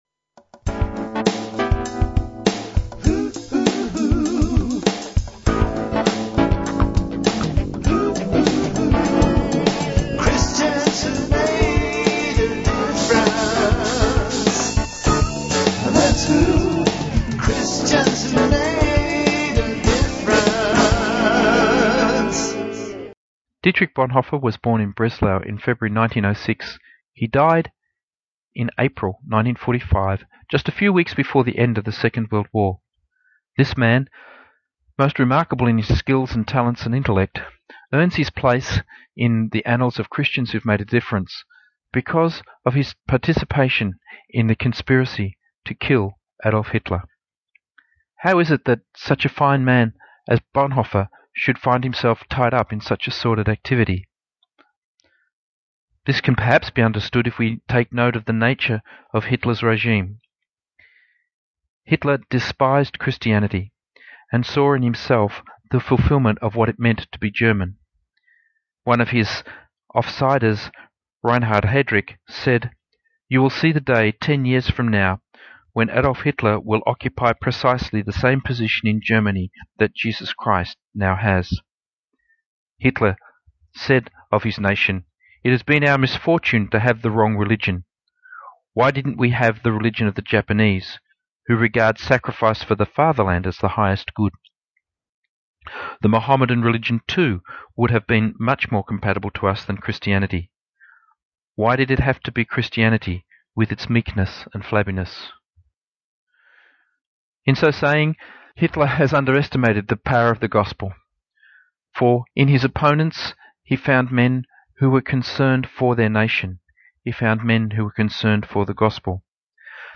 Christian, stories